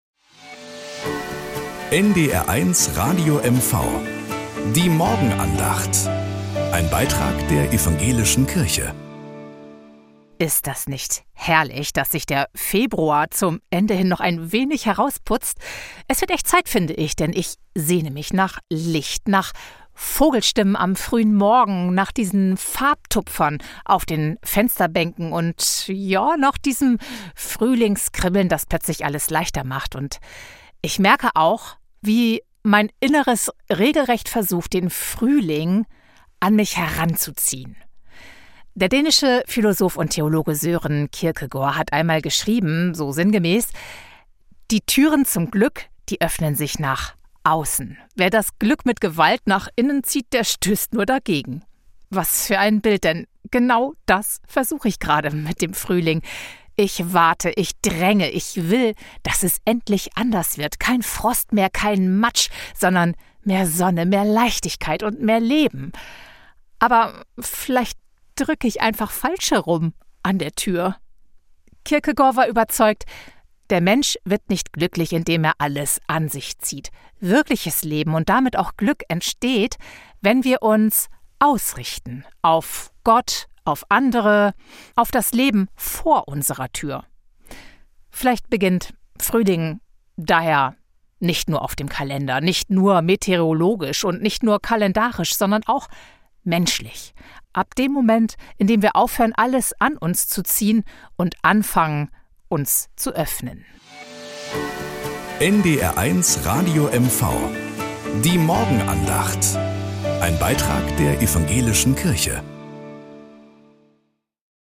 Morgenandacht auf NDR 1 Radio MV